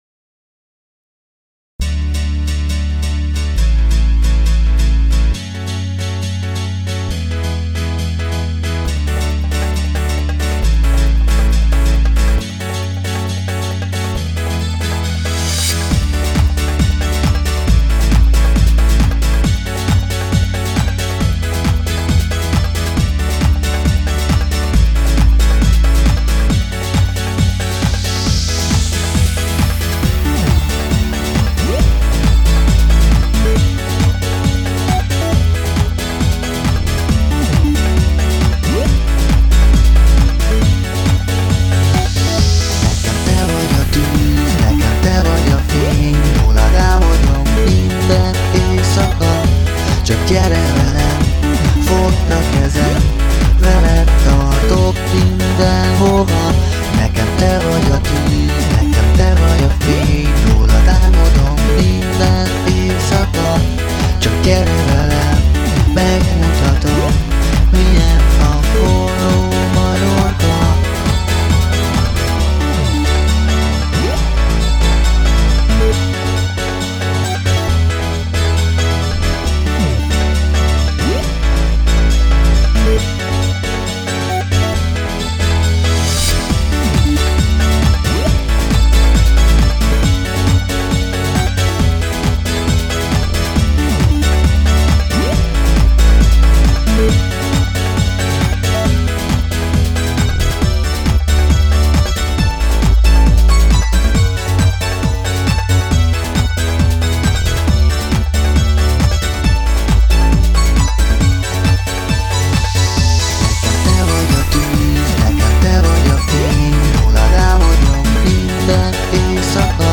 Fenn a mennyben dance style (instrumental)